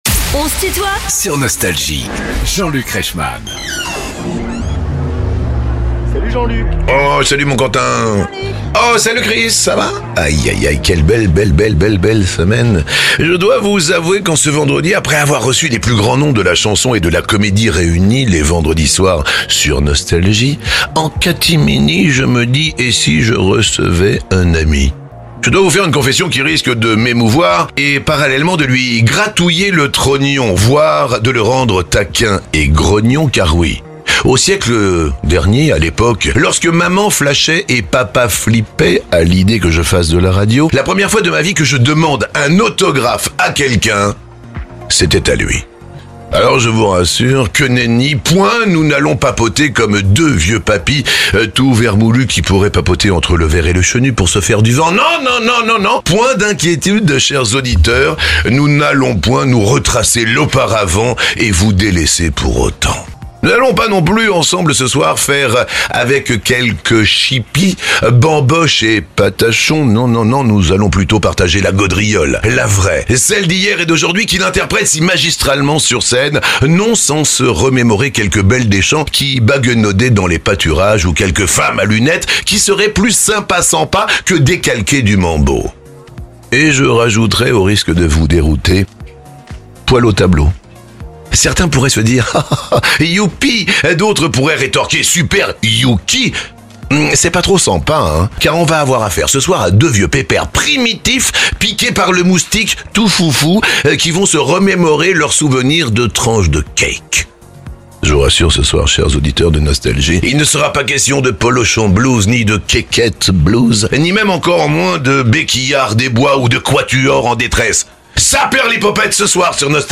Richard Gotainer est l'invité de "On se tutoie ?..." avec Jean-Luc Reichmann (partie 1) ~ Les interviews Podcast
L'artiste aux multiples tubes des années 80 comme "Le Mambo du décalco" ou "Femmes à Lunettes" est l'invité de "On se tutoie ?..." avec Jean-Luc Reichmann De la musique, des mots et de l'humour, encore et toujours !